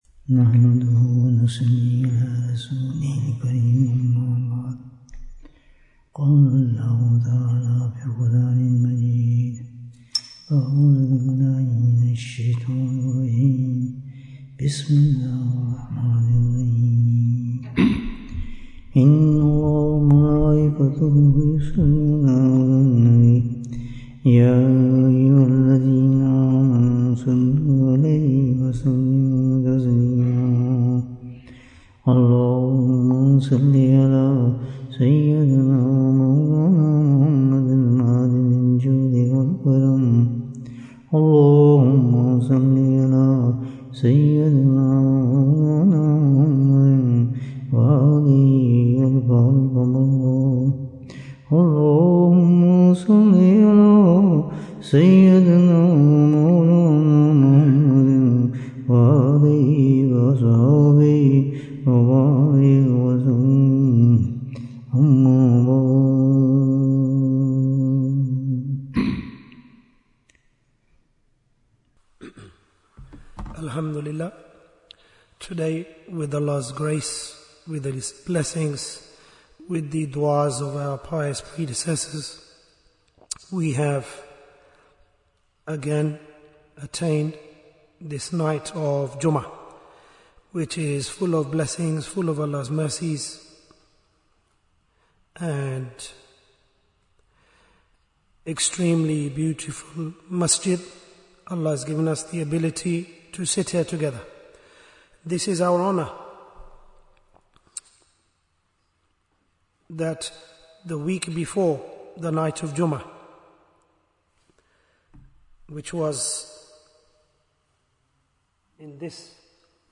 Bayan, 83 minutes27th November, 2025